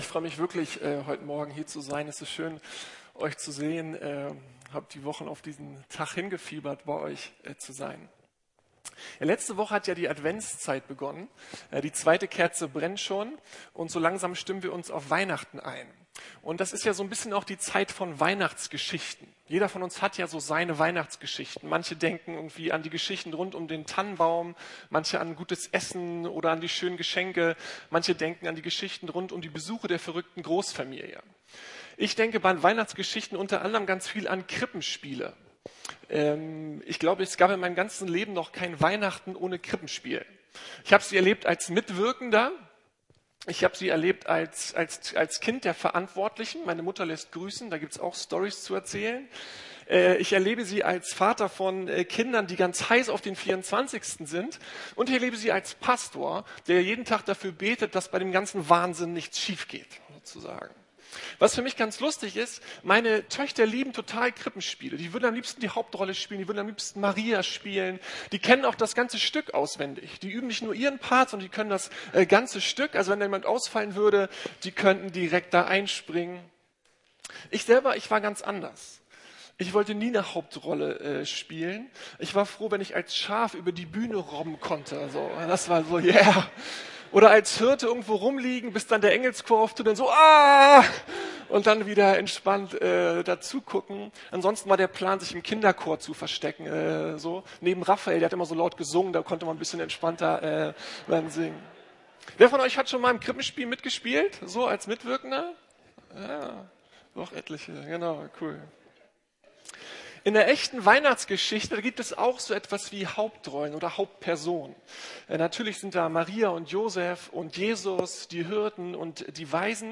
Hannah - Teil der großen Geschichte ~ Predigten der LUKAS GEMEINDE Podcast